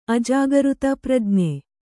♪ ajāgřta prajne